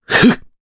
Mitra-Vox_Casting1_jp.wav